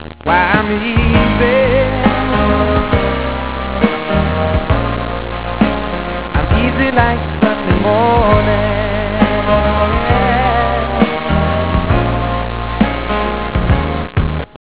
My theme song (Sorry for the quality...I think Edison recorded it)